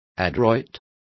Complete with pronunciation of the translation of adroit.